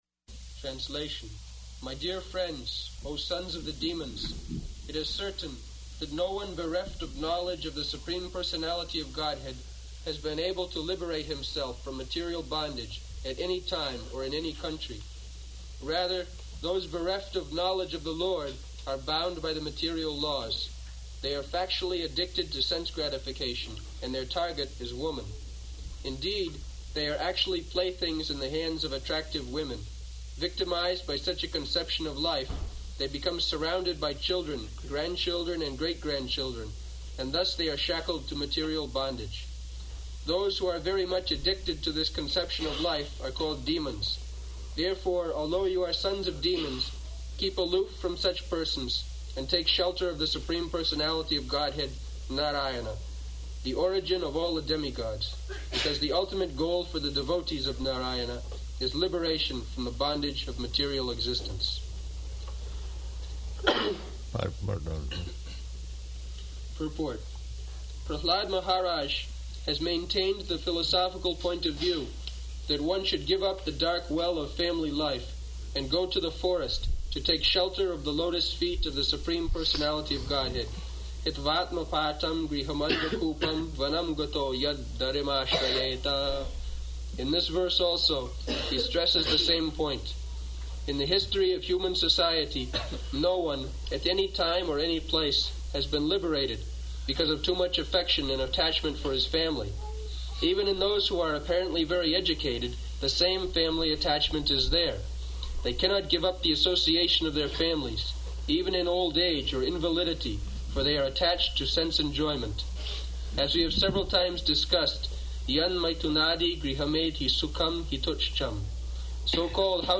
Location: New Vrindavan